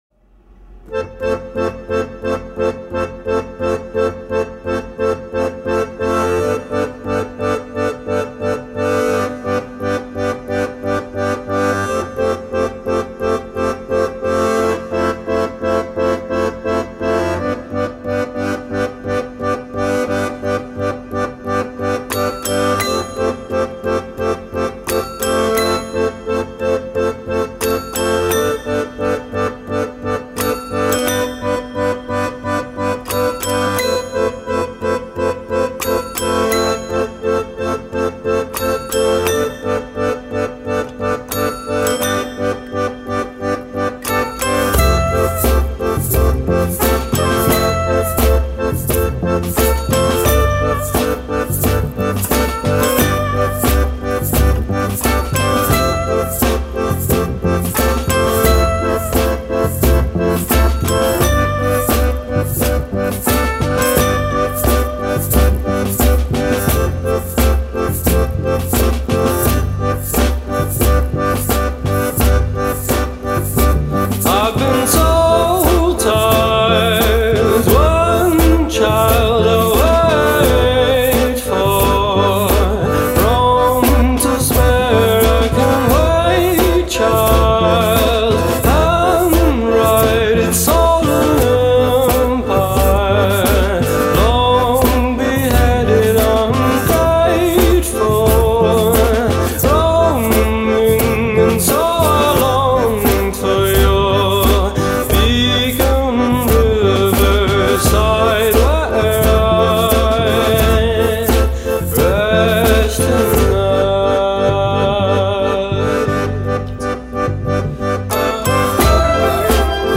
BEIRUT VIENTOS DE MELANCOLÍA (REMAKE) Programa Radiofónico